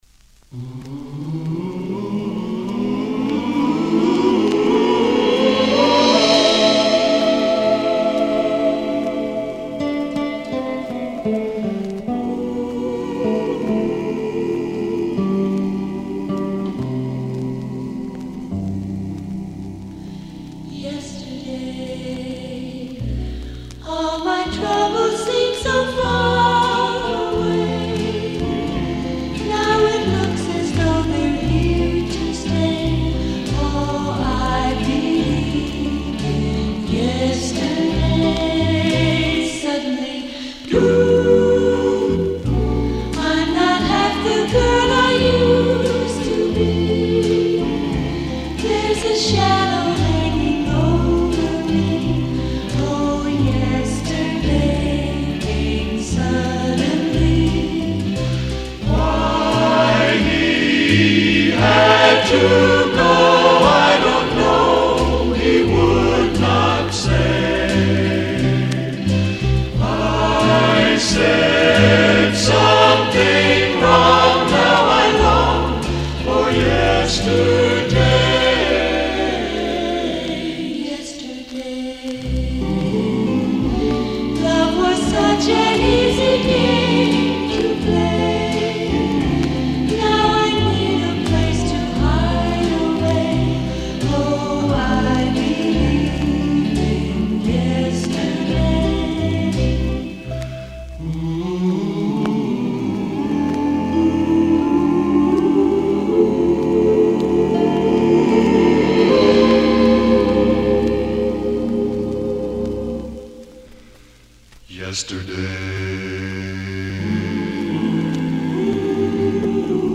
deze link naar de declicked versie van de plaat.